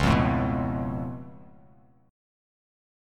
C#+7 chord